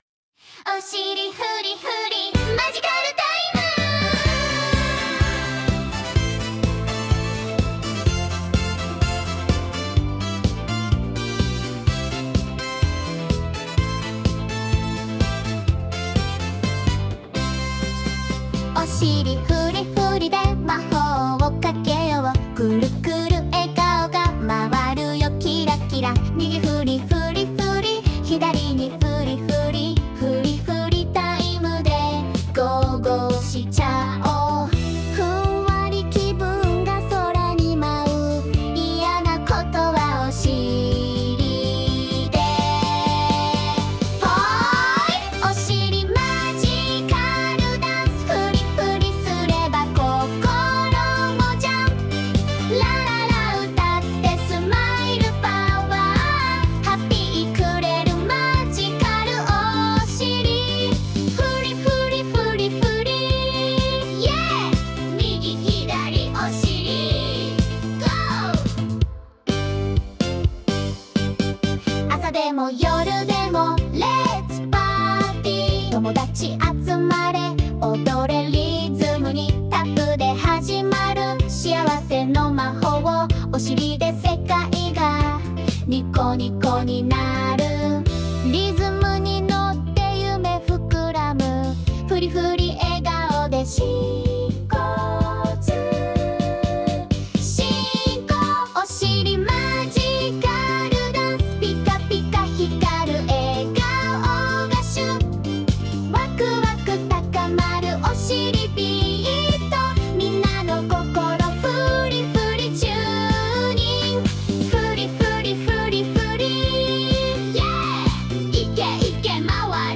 作曲：最新AI